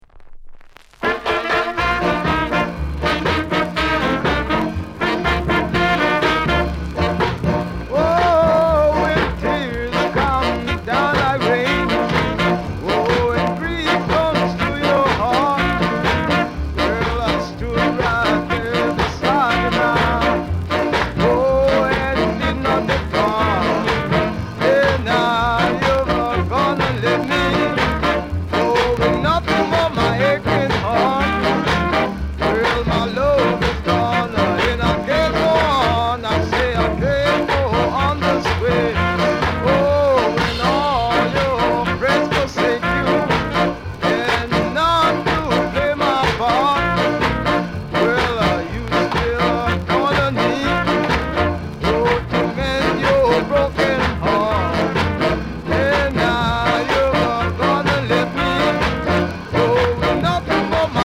AUTHENTIC SKA INST